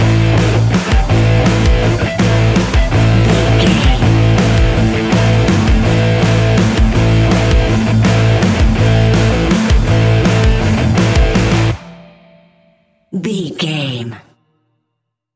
Ionian/Major
A♭
hard rock
heavy metal
instrumentals